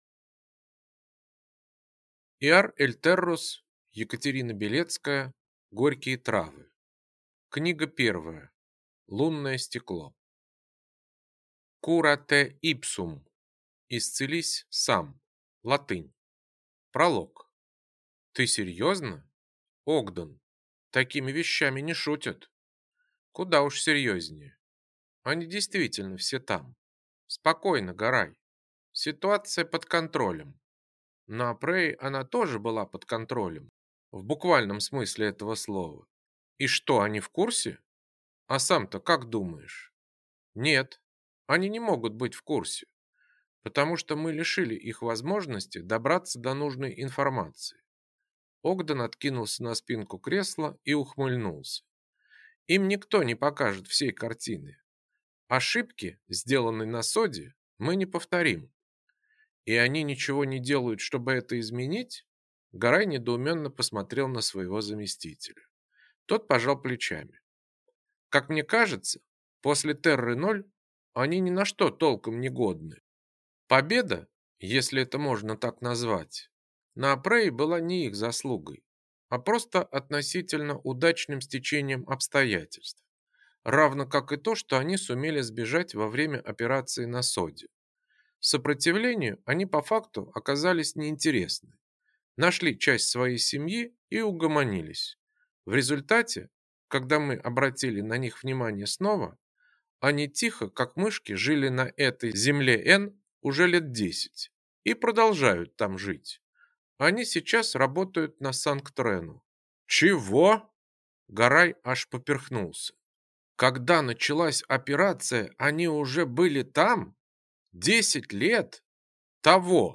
Аудиокнига Лунное стекло | Библиотека аудиокниг
Прослушать и бесплатно скачать фрагмент аудиокниги